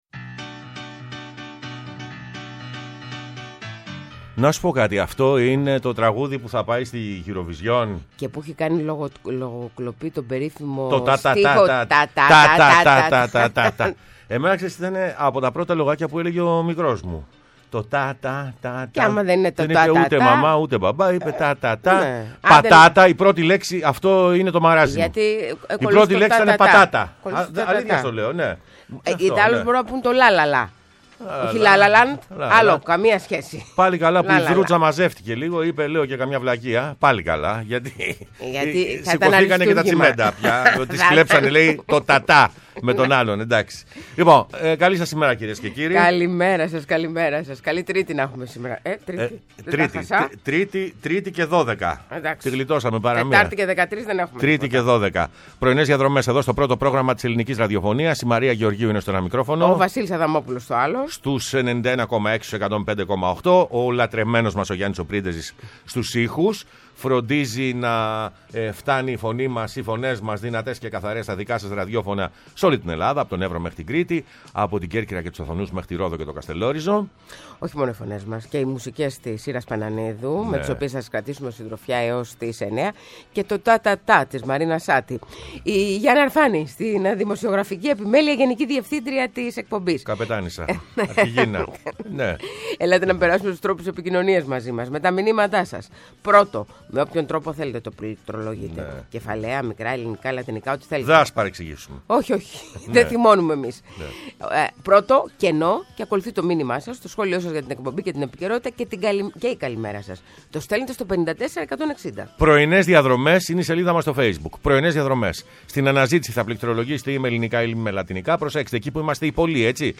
Καλεσμένος σήμερα ο Δημήτρης Μαρκόπουλος, Βουλευτής ΝΔ και Πρόεδρος Εξεταστικής Επιτροπής Βουλής για την τραγωδία των Τεμπών.